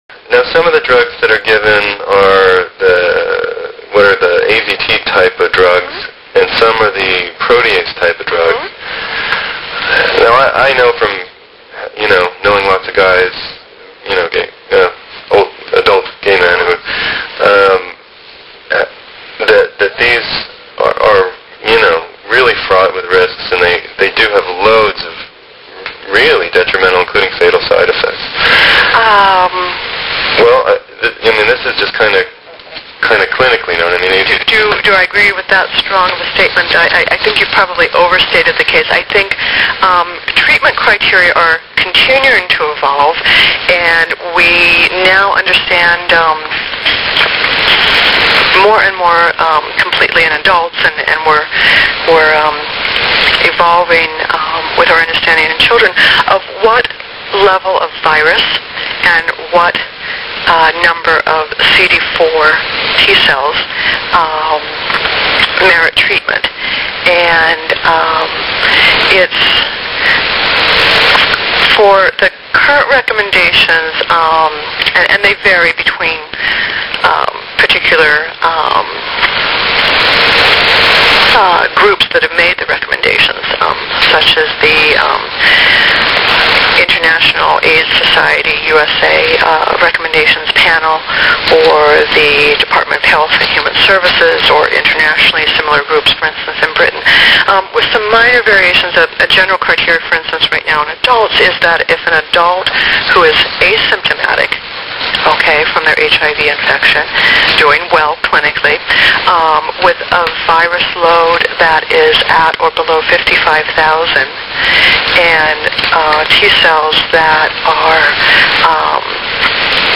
Listen To ICC Interview Part 5 Click The Icon To Listen To Part 5 Of The Interview: Viral Load, T-cells and AZT